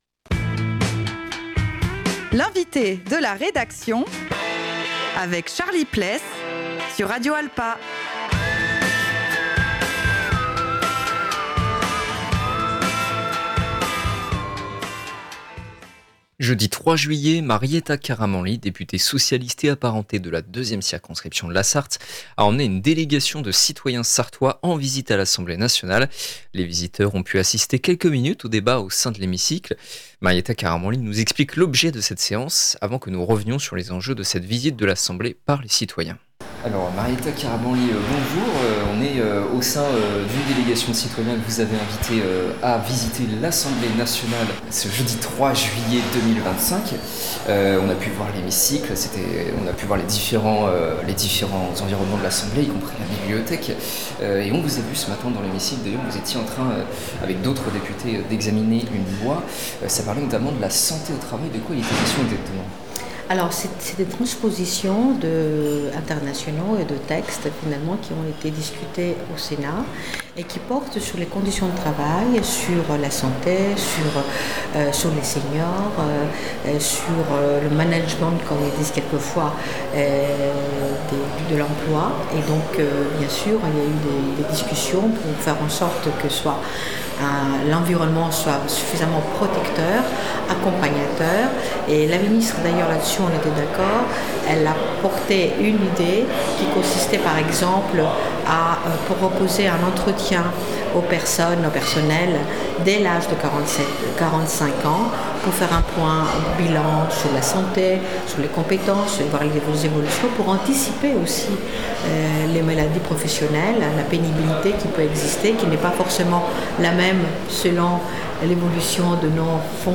Marietta Karamanli nous explique l’objet de cette séance avant que nous revenions sur les enjeux de cette visite de l’Assemblée par des citoyens.